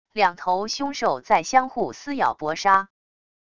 两头凶兽在相互撕咬搏杀wav音频